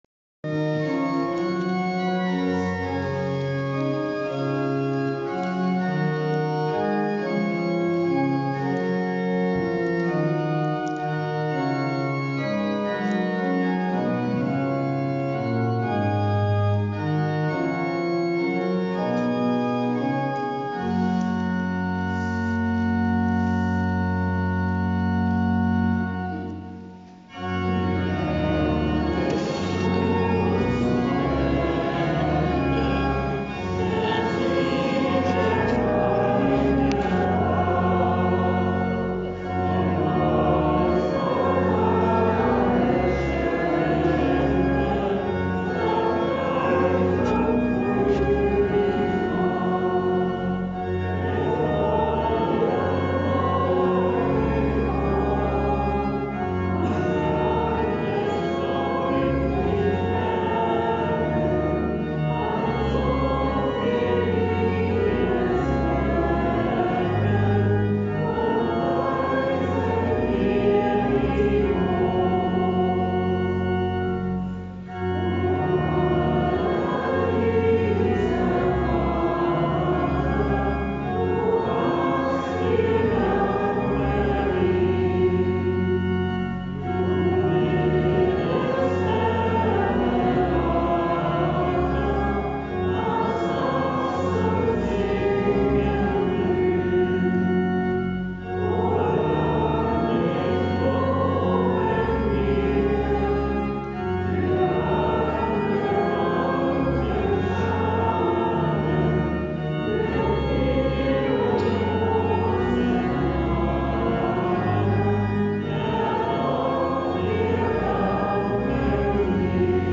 So auch in der Marienkirche in Bad Belzig.
Erntedank-Bad-Belzig.mp3